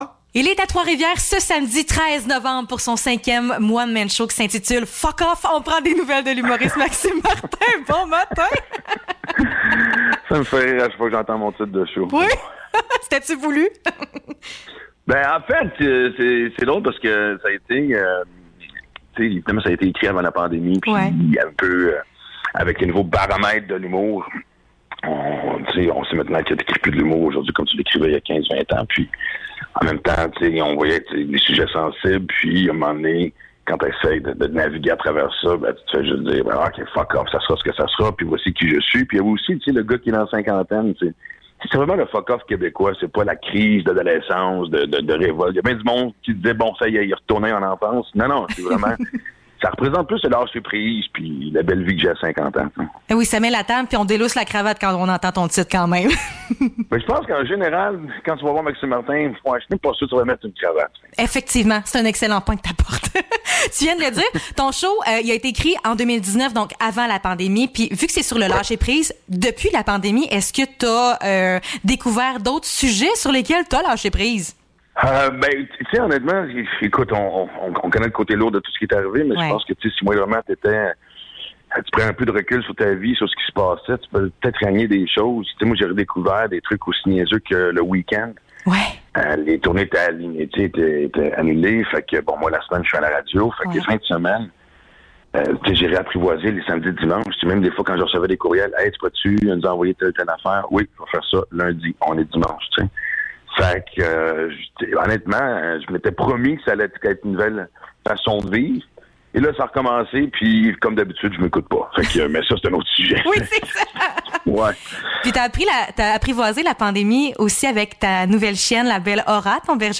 Entrevue avec l’humoriste Maxim Martin (11 novembre 2021)